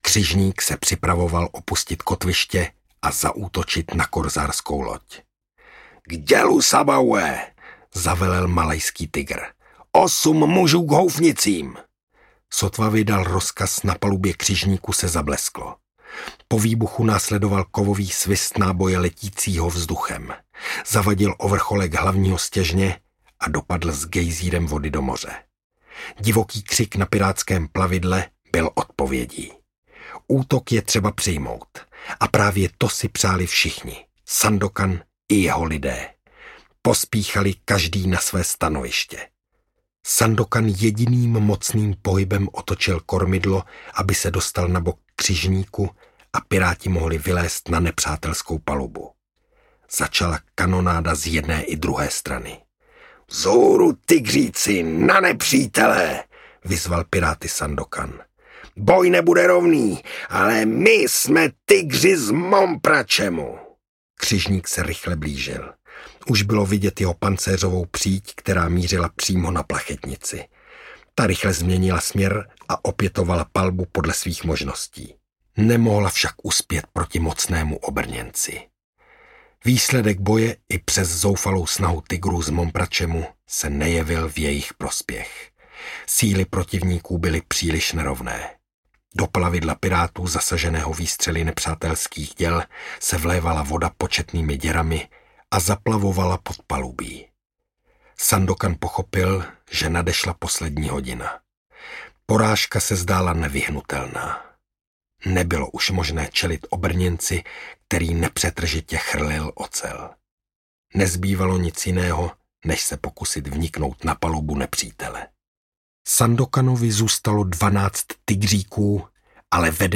Sandokan I: Tygři z Mompracemu audiokniha
Ukázka z knihy
Vyrobilo studio Soundguru.